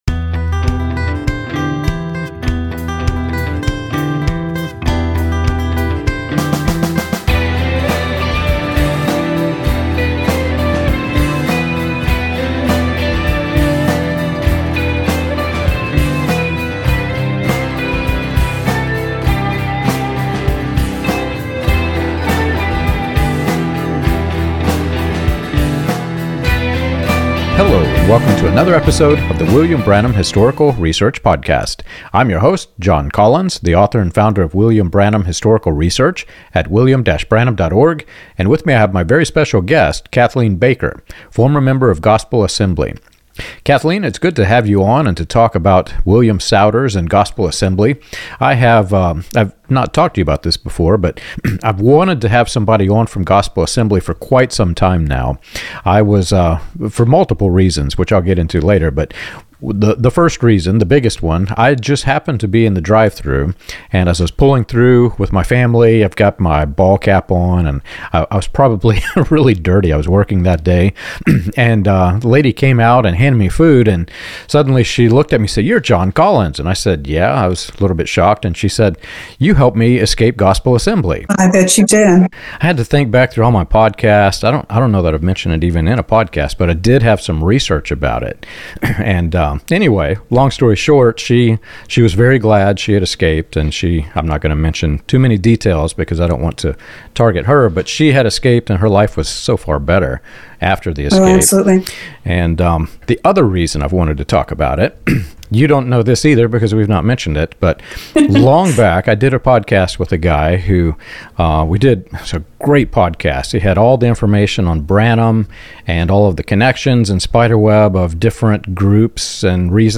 in-depth conversation